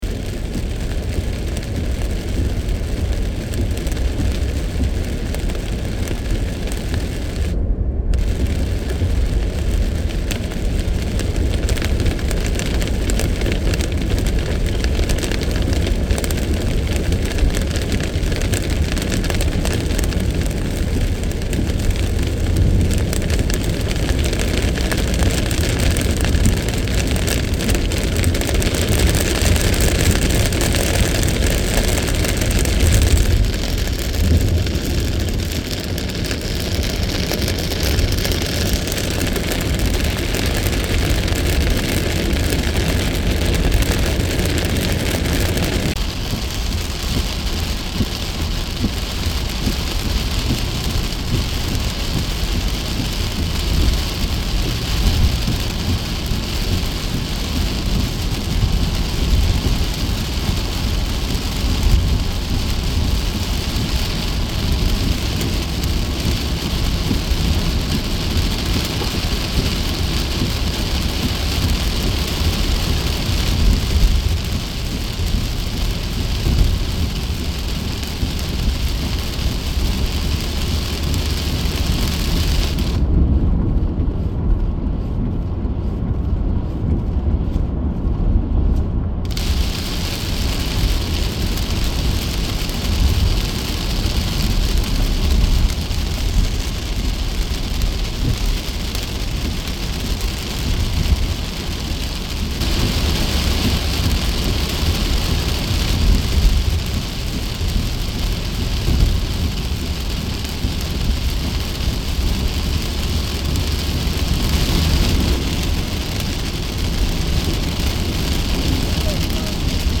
18. Звук едущей машины ночью в ливень, сильный дождь, шум дворников и капель дождя по автомобилю
ezda-v-liven.mp3